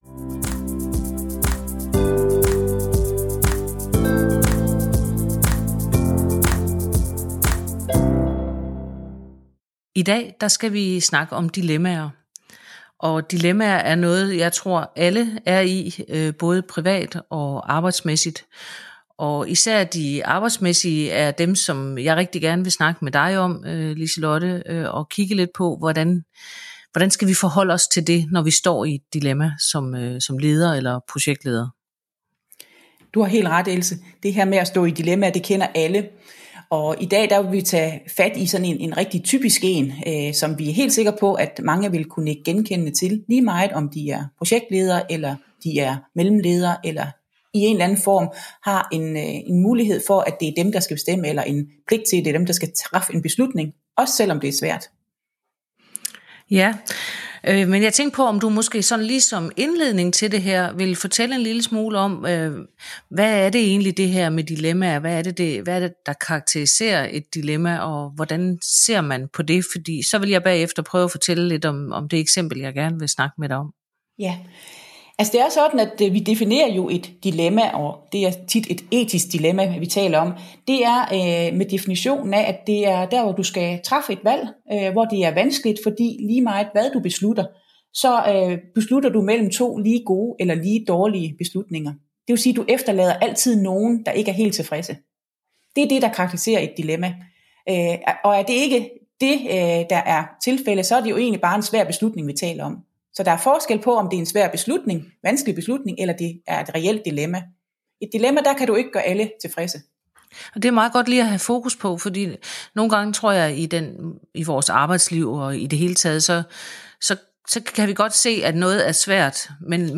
tale sammen om arbejdsmæssige dilemmaer, og hvordan du kan håndtere dem som projektleder og leder.